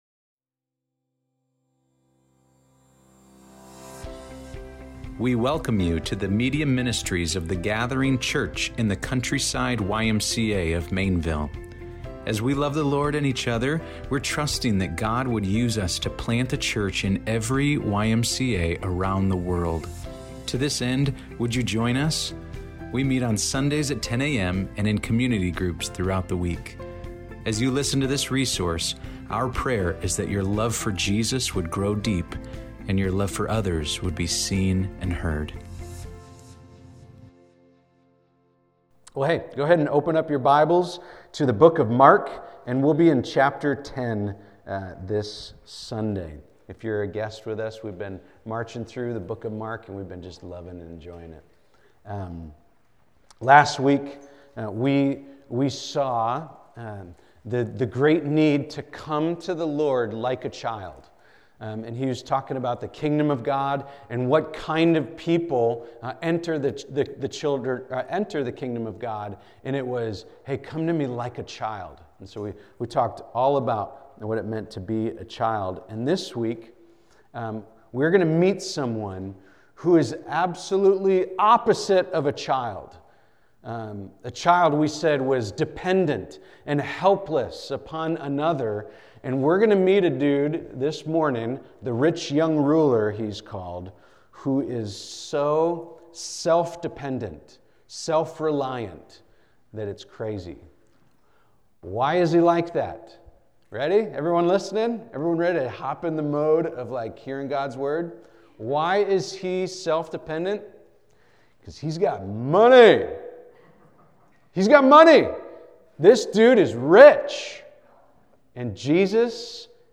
The Gathering City Maineville Sermons